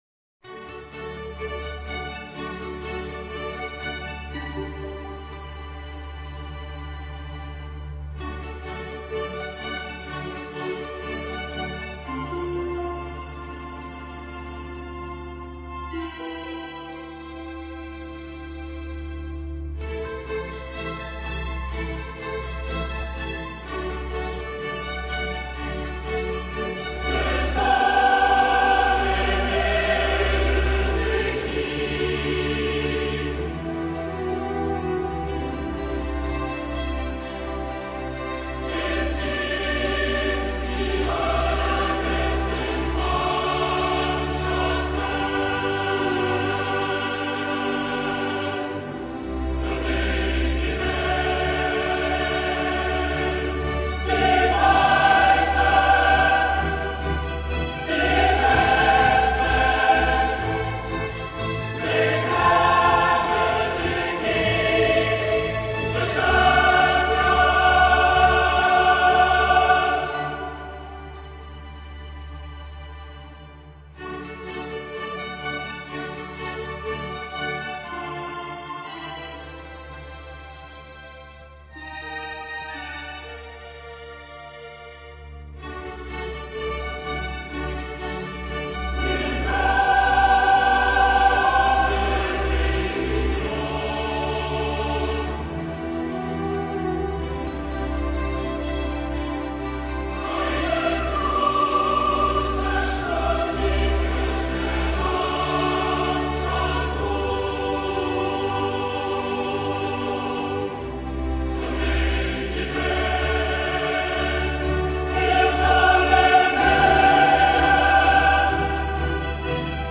〽 ژانر بی کلام